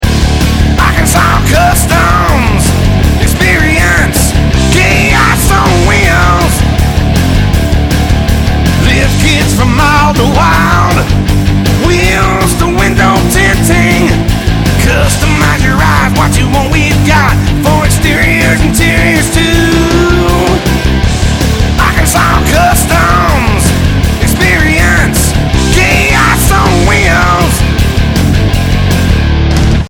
National quality jingles at competitive prices!